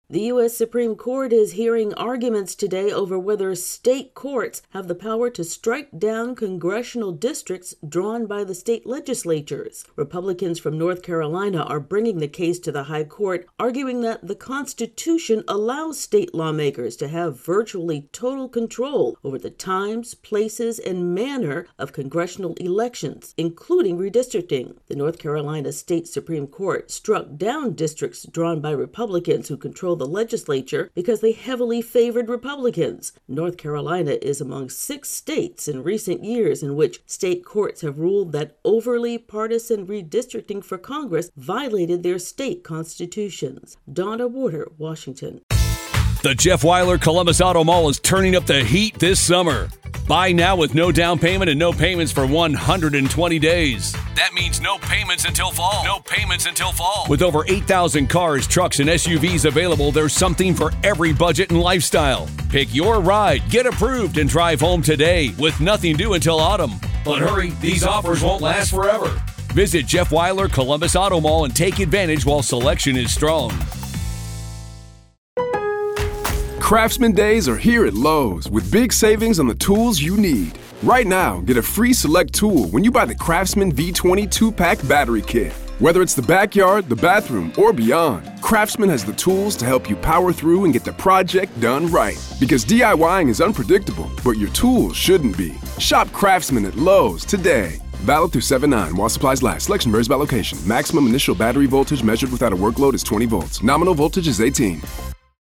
reports on a case before the Supreme Court that could reshape congressional elections.